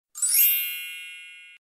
Âm thanh "magic fairy" | Hiệu ứng âm thanh độc lạ ghép và chỉnh sửa video